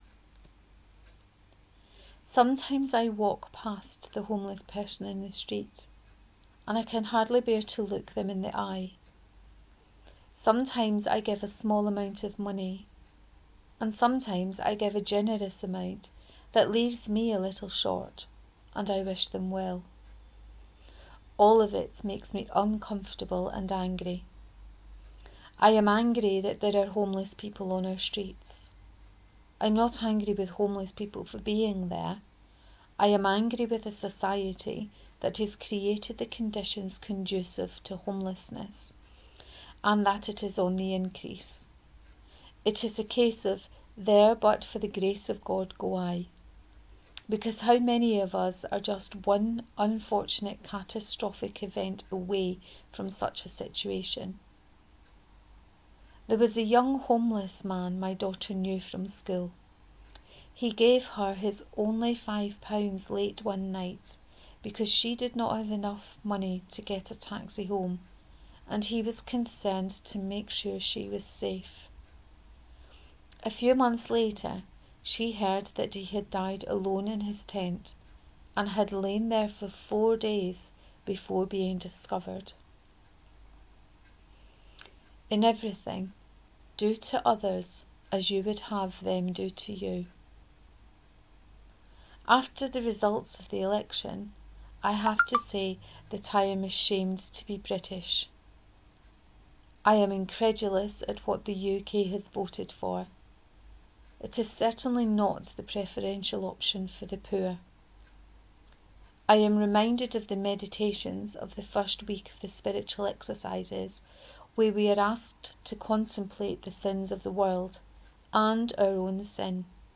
Shoving yer Grannie aff a bus 5 : reading of this post